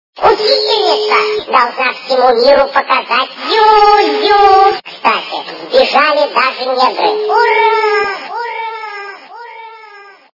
» Звуки » Смешные » Голос - Учитель должна показать всему миру зюзю